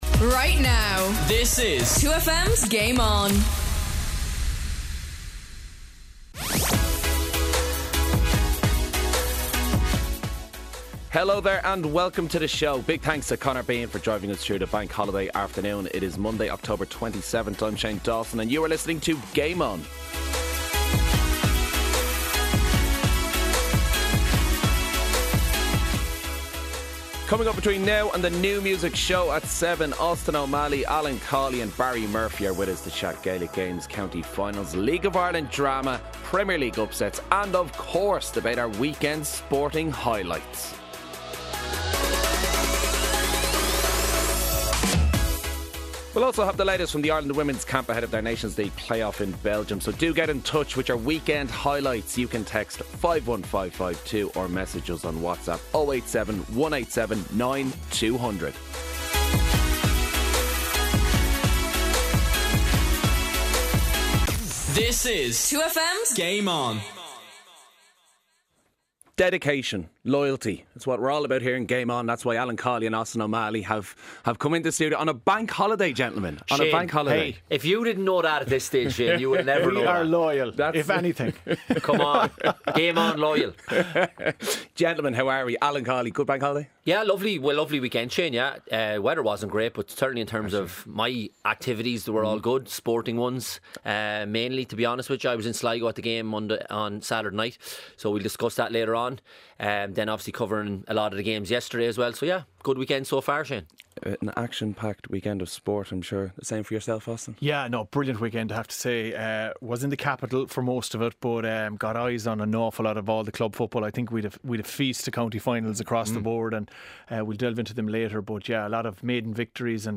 in studio. Up for discussion we have League of Ireland, the EPL and the weekends county finals.